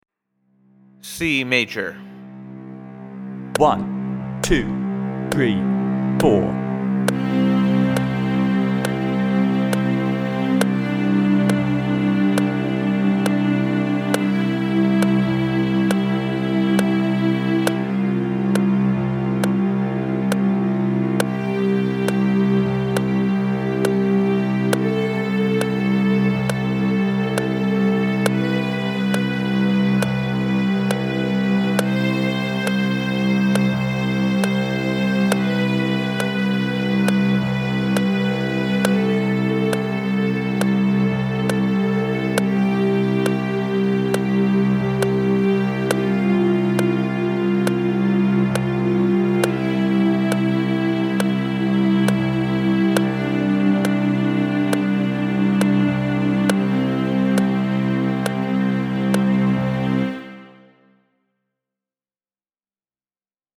The scales are played in whole notes, with quarter note at about 70 bpm.
C Major
01_C_Major_Scale_with_Drone.mp3